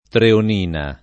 treonina [ treon & na ]